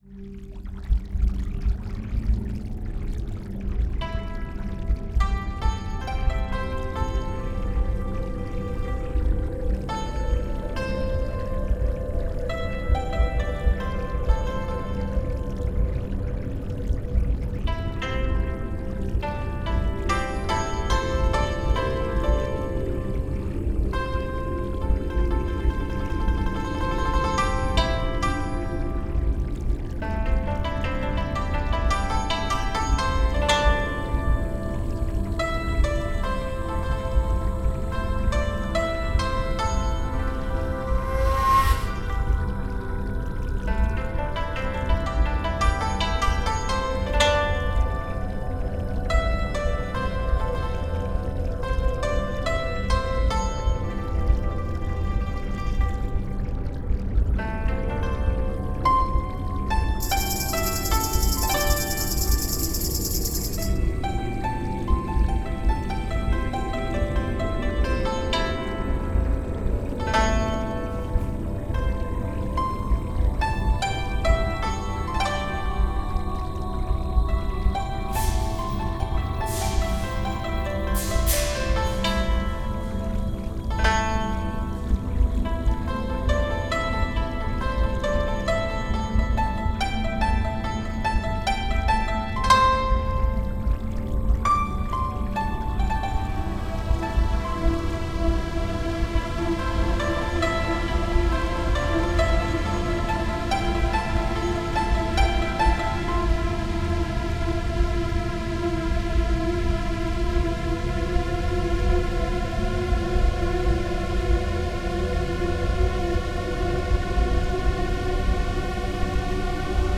cinematic soundtrack
Pls. don't buy a ticket for Dolphin show - Thank you ♥ The soundtrack is full cinematic.